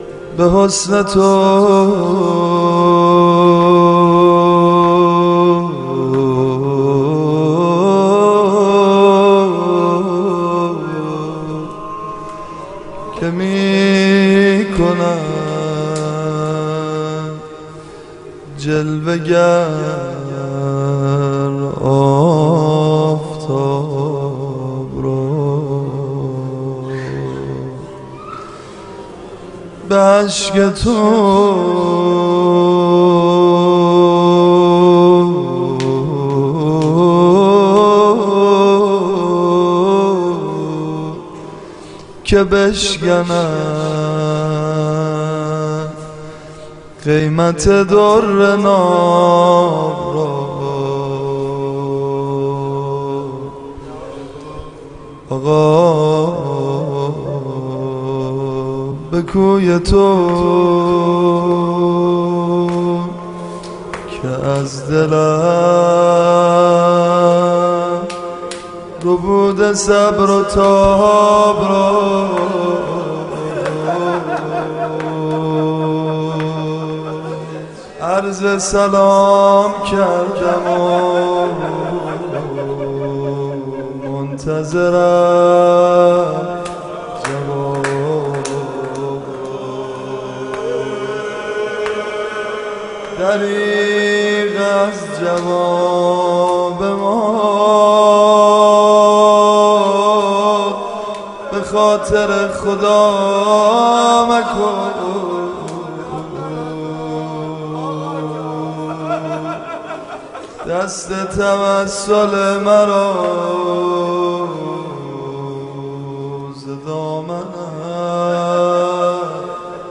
نوحه خوانی | به حُسن تو که میکند جلوه‌گر آفتاب را